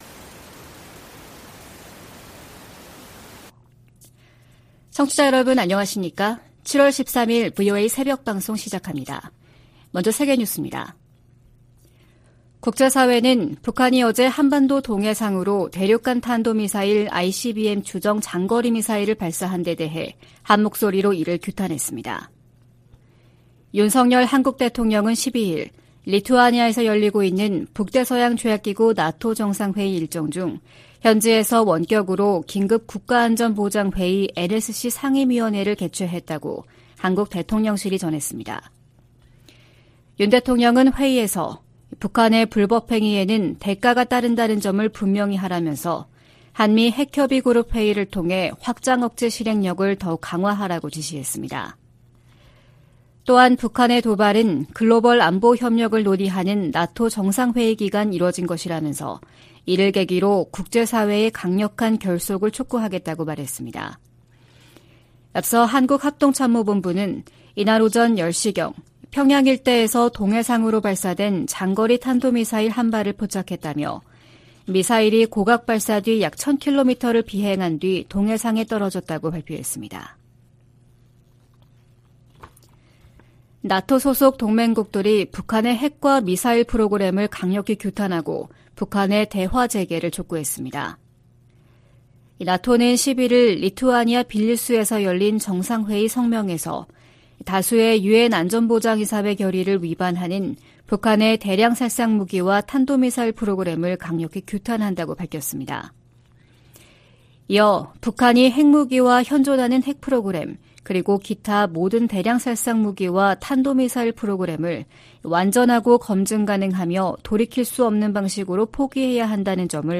VOA 한국어 '출발 뉴스 쇼', 2023년 7월 13일 방송입니다. 한국 정부가 북한 정권의 대륙간탄도미사일(ICBM) 발사를 규탄하며 불법 행위에는 대가가 따를 것이라고 경고했습니다. 미국과 한국, 일본의 북 핵 수석대표들도 북한의 ICBM 발사는 유엔 안보리 결의를 위반하는 심각한 도발로, 어떤 이유로도 정당화할 수 없다고 비판했습니다. 미 국무부는 미군 정찰기가 불법 비행했다는 북한 정권의 주장은 근거가 없다고 지적했습니다.